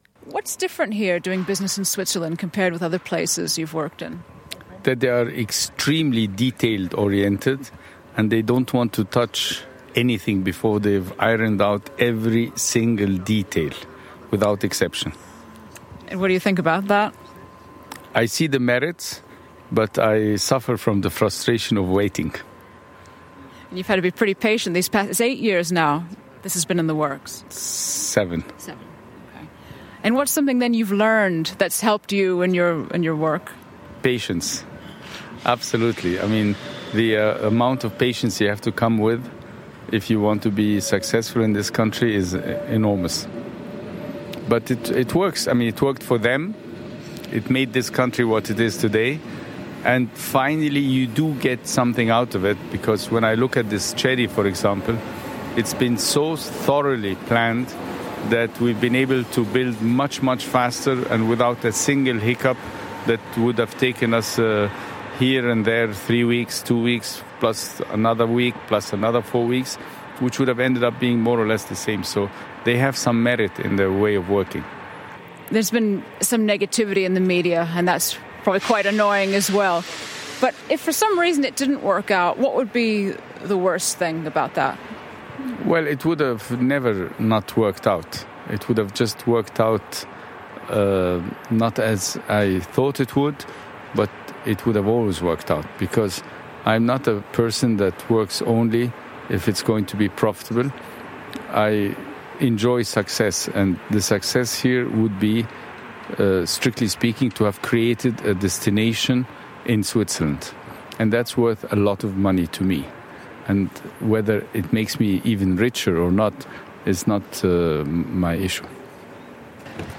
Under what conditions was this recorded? On site in Andermatt